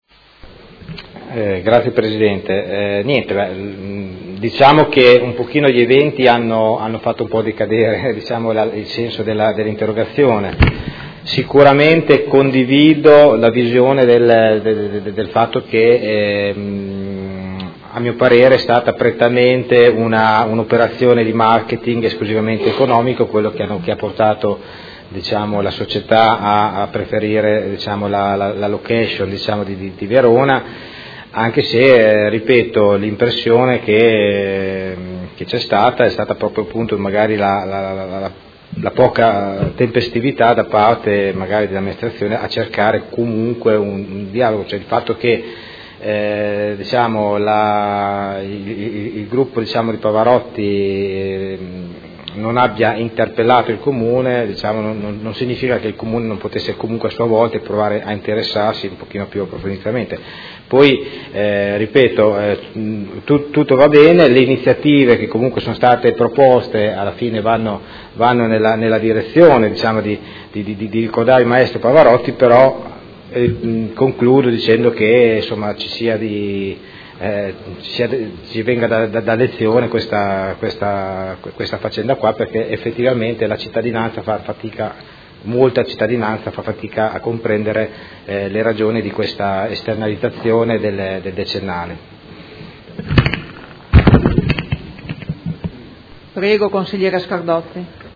Seduta del 23/03/2017 Replica a risposta Assessore. Interrogazione dei Consiglieri Stella e Rocco (Art.1-MDP) avente per oggetto: Eventotributo per il decennale della scomparsa di Luciano Pavarotti: perché l’Amministrazione ha permesso che venisse organizzato a Verona?